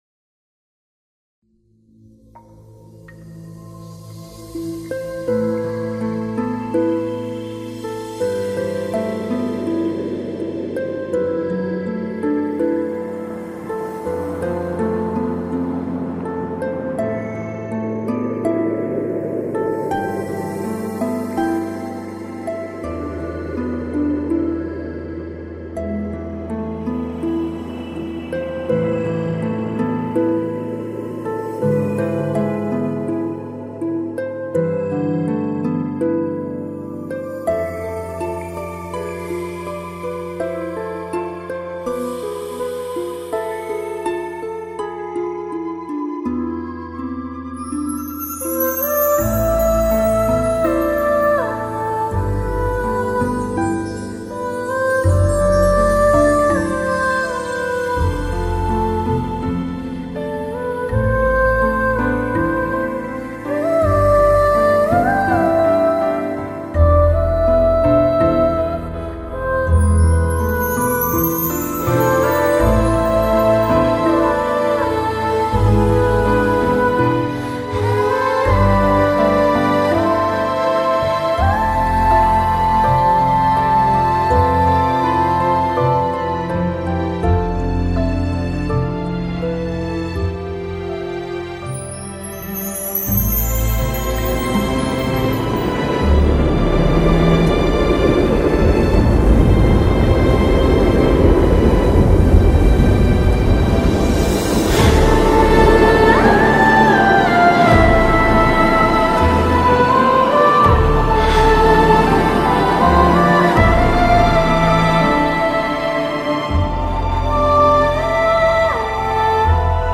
官方宣传动画